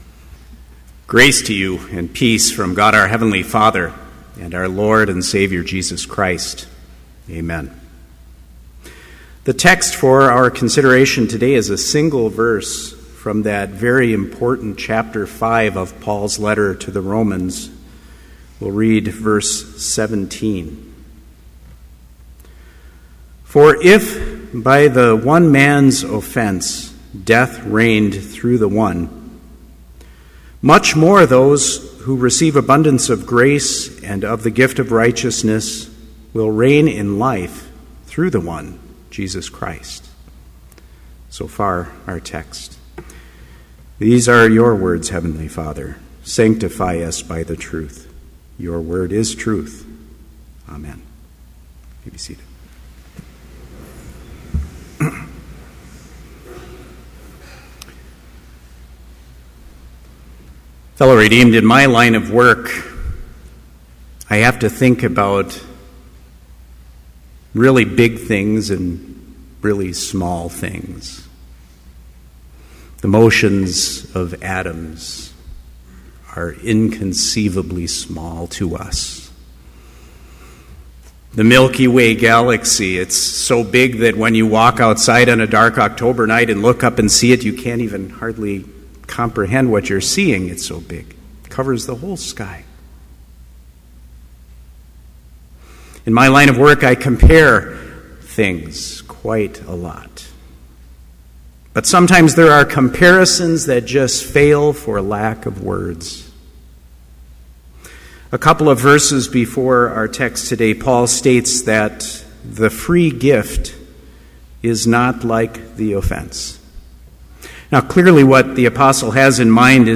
Complete service audio for Chapel - October 2, 2013